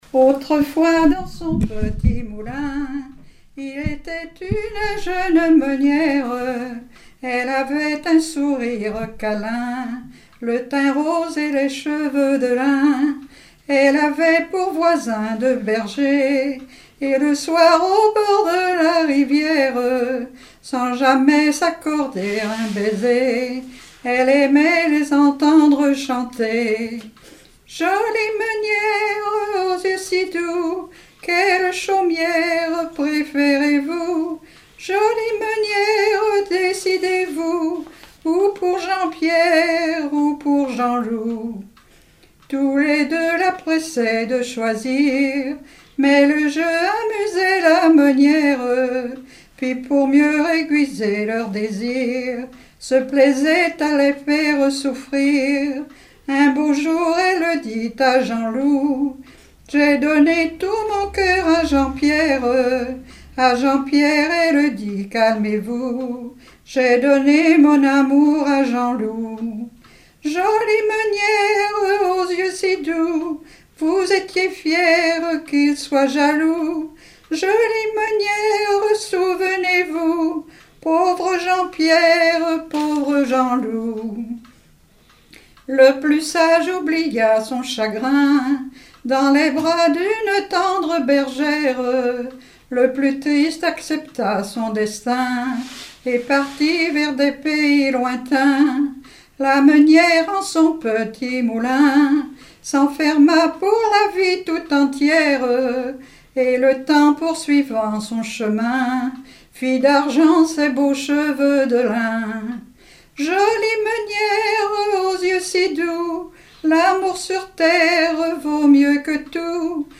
Genre strophique
Chansons traditionnelles et populaires
Pièce musicale inédite